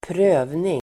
Uttal: [²pr'ö:vning]